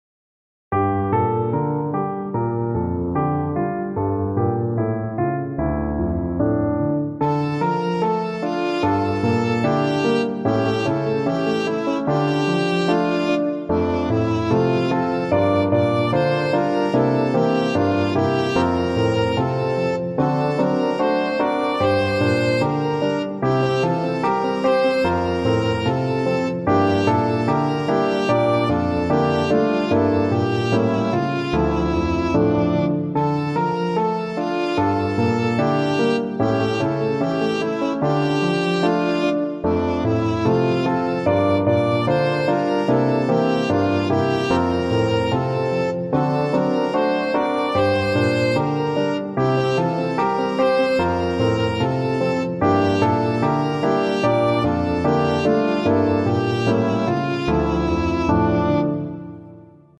Караоке.